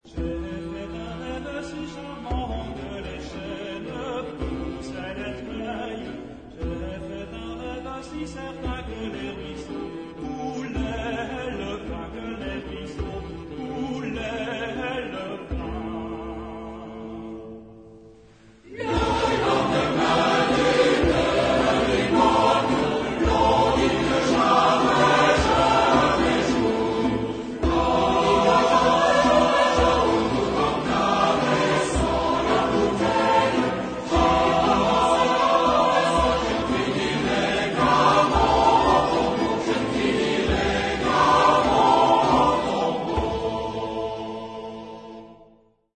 Genre-Stil-Form: Trinklied
Chorgattung: SATB  (4 gemischter Chor Stimmen )
Tonart(en): e-moll
Herkunft: Angoumois (Frankreich)